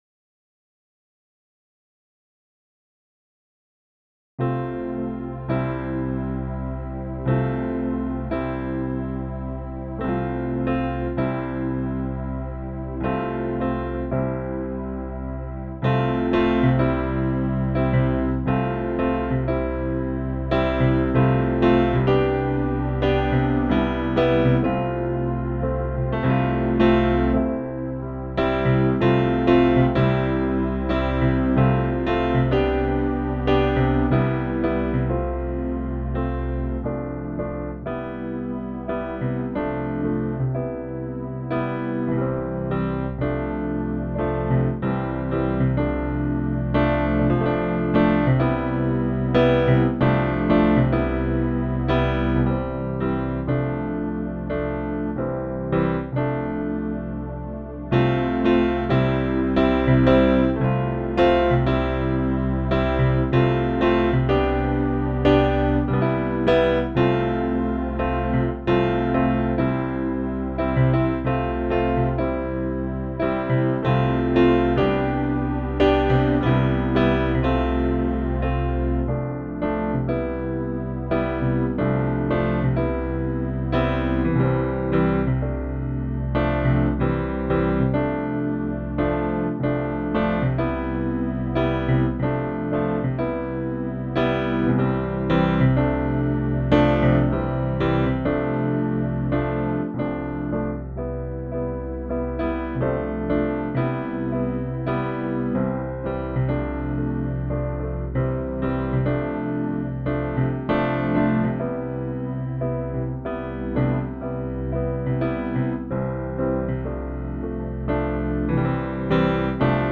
Pedal Piano
This has the same pedal bass notes all the way through.
pedal-piano.mp3